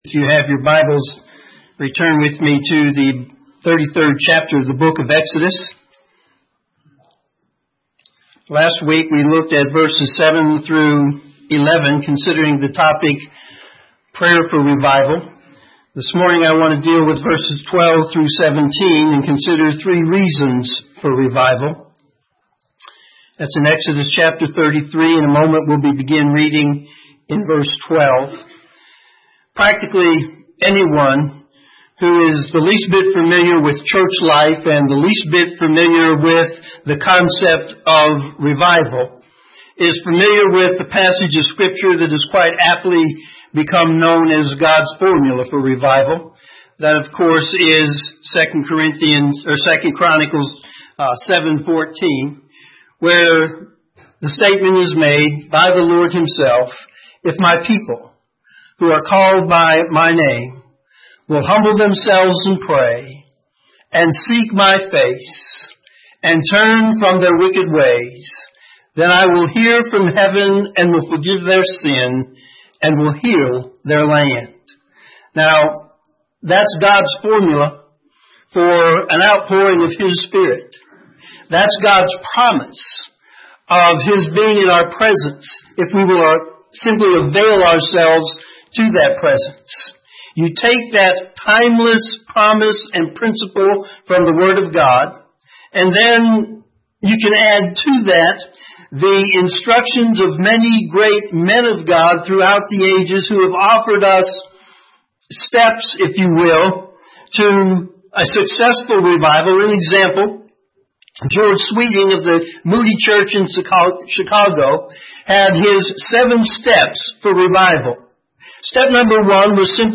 Morning Sermon Exodus 33:12-17